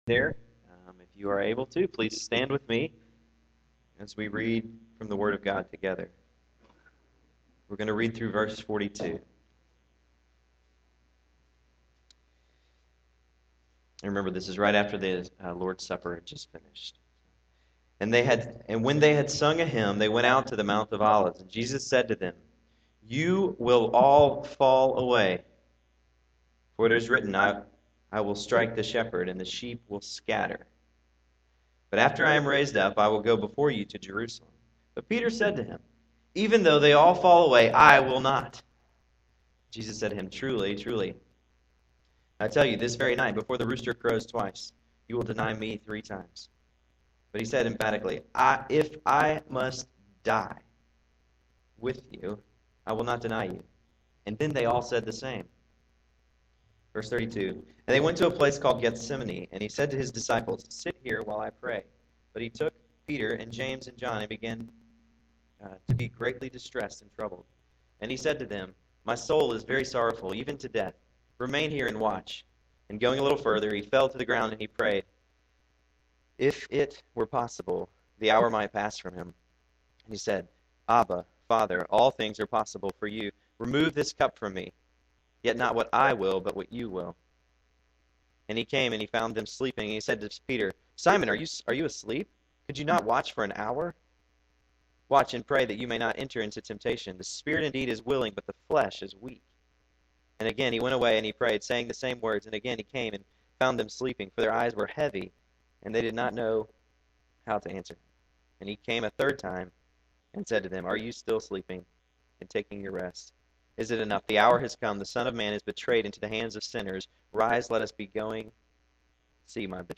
November 15, 2009 AM Service
After a time of greeting each other, the choir ensemble sang the first three stanzas of “O That Will Be The Glory” and the congregation joined in on the last stanza.